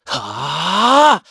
Siegfried-Vox_Casting3_kr.wav